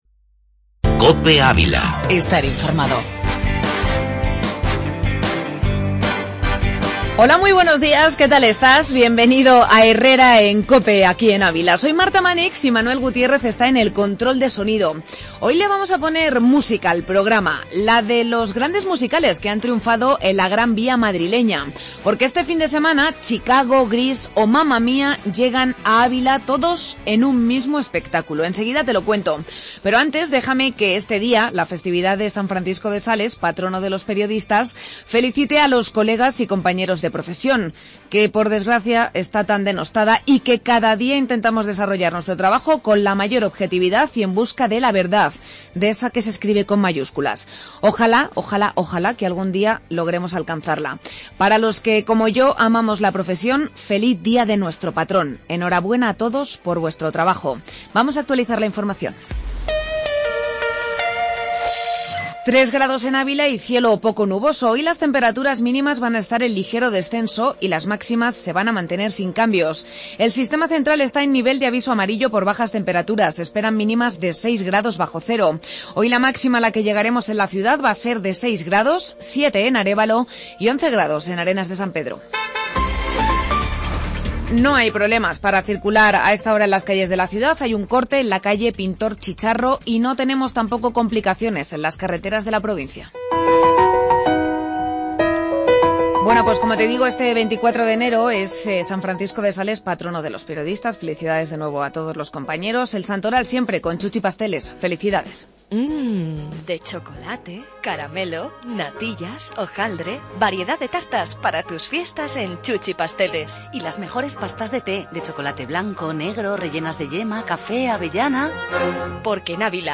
AUDIO: Entrevista espectáculo desde la Gran Vía